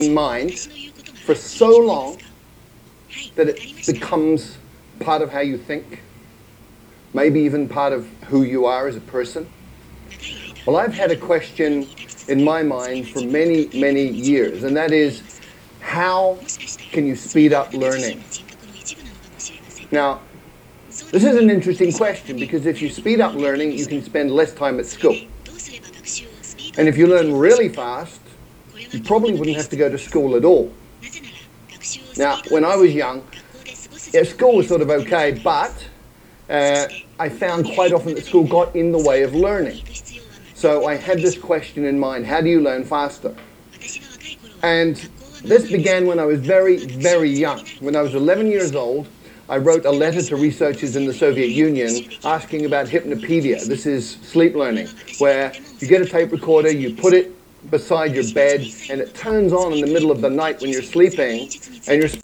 レビュー時には試しに、さまざまな分野の著名人による講演を視聴できる「TED（Technology Entertainment Design）」の音声を、この傍聴通訳モードで翻訳させてみた。
録音データでは、イヤホンからの通訳音声が裏でかすかに聴こえるので、注意して聴いてみてほしい。